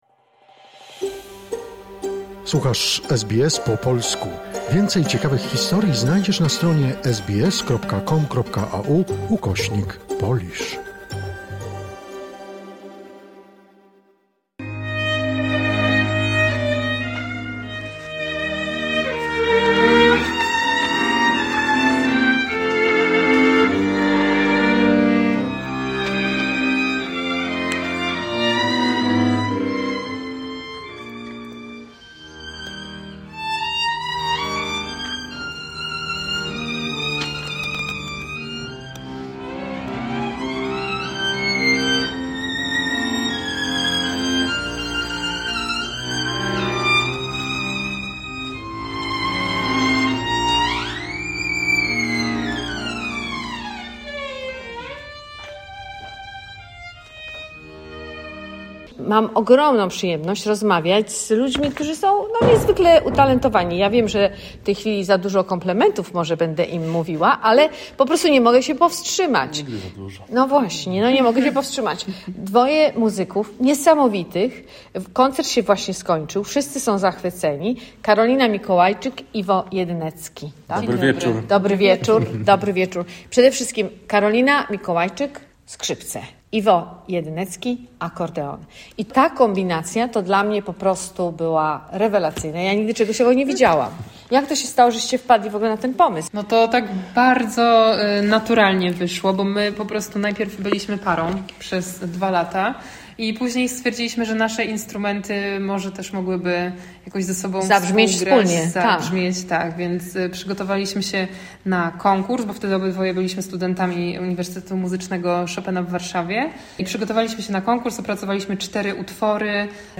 W czwartek, 25 września 2025 roku, w Konsulacie RP w Sydney miał miejsce niezapomniany koncert dwojga młodych muzyków przybyłych z Polski.
skrzypce
akordeon